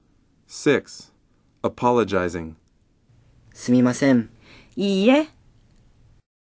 dialog1_6.mp3